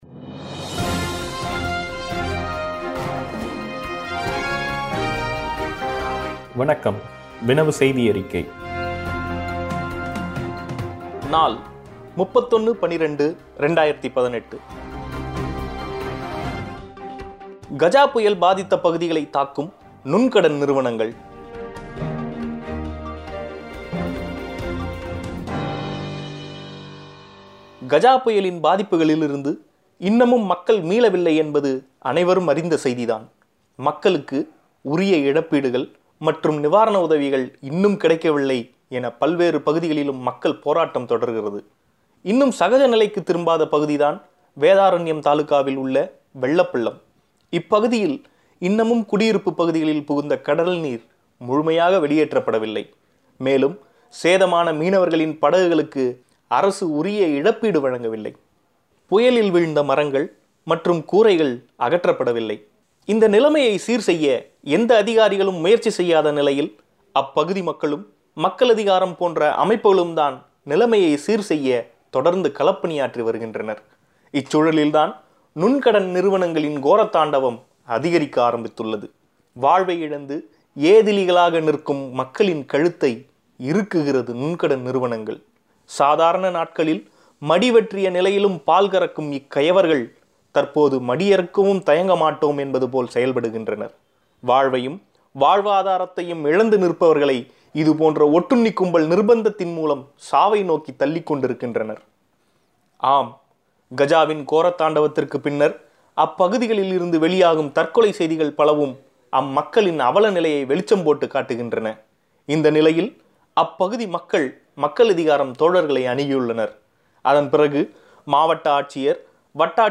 ஓட்டுக்குப் பணம் வாங்குவதால் தமிழகம் முழுவதும் தேர்தலைப் புறக்கணிக்கலாமா ? கேள்வி பதில் ... கஜா புயல் பாதித்த பகுதிகளைத் தாக்கும் நுண்கடன் நிறுவனங்கள் ! ... உள்ளிட்ட கட்டுரைகள் ஒலி வடிவில்.